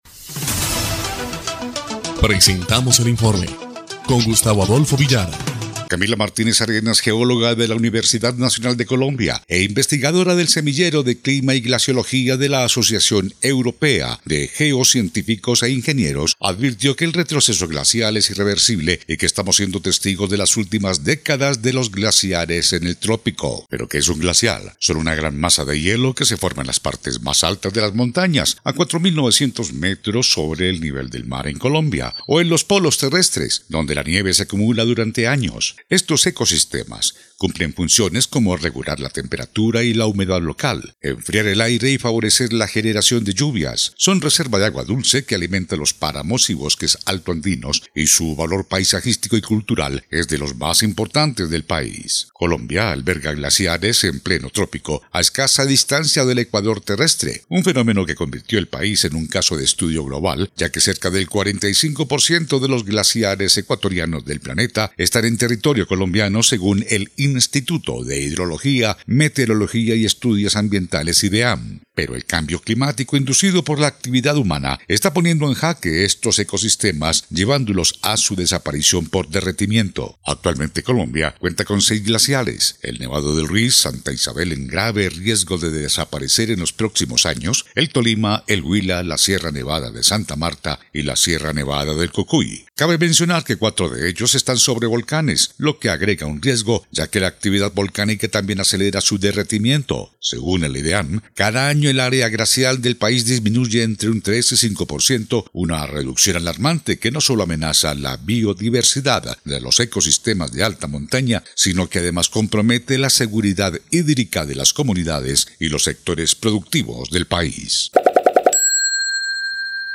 EL INFORME 3° Clip de Noticias del 25 de abril de 2025